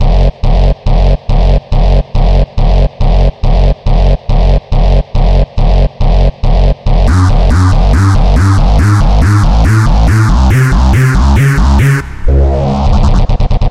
遗骸 " 床垫上的木头 002
描述：木头落在床垫上。户外。
Tag: 下降 秋天 货架 下探